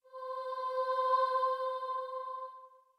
Starting Note Stop Listen Stop Master Sight Singing Become a SightReadingMastery member and get unlimited, professionally-composed sight reading, live help from music teachers and much more.
starting_note.mp3